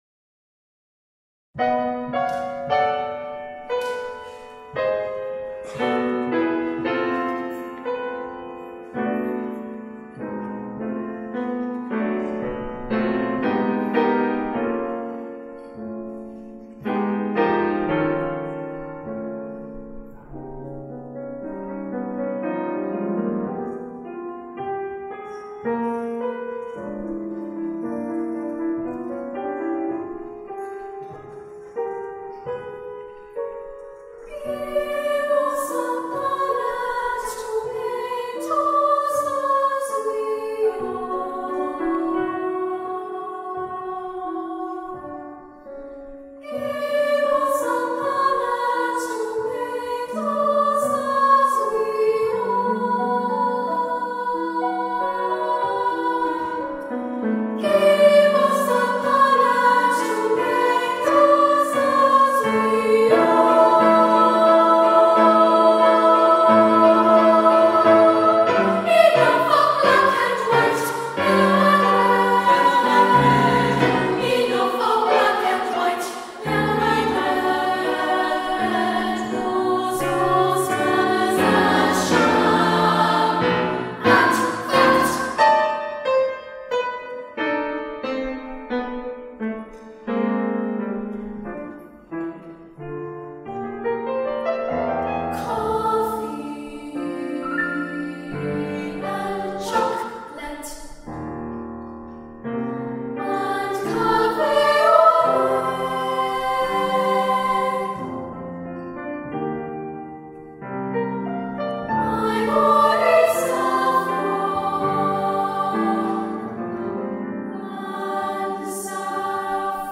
SSA, piano